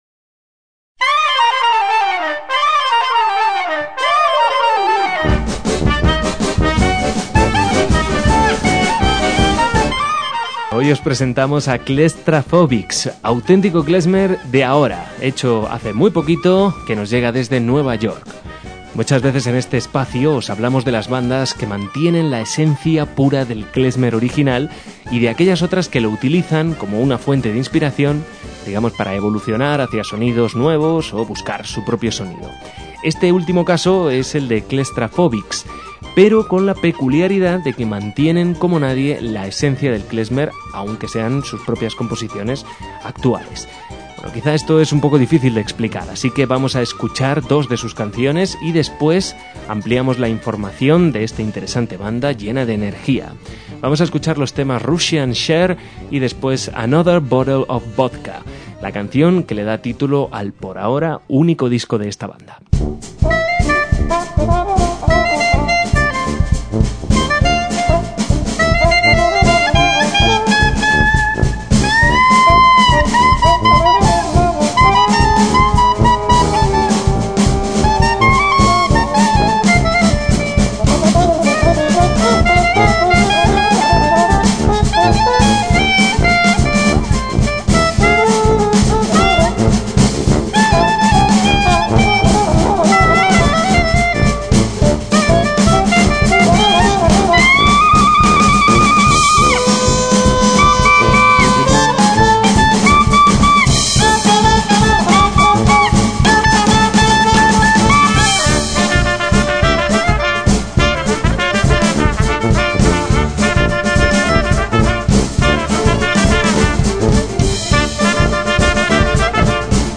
MÚSICA KLEZMER
acordeón
tuba
clarinete
trompeta